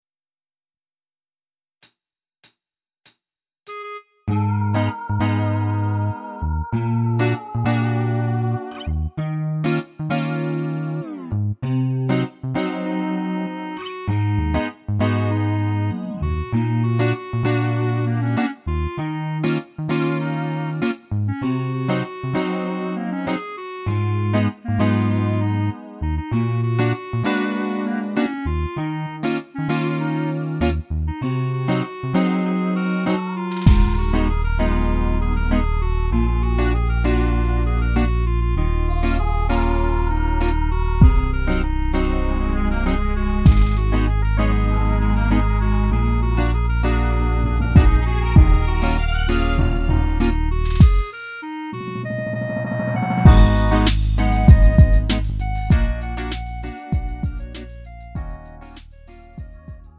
음정 -1키 3:08
장르 가요 구분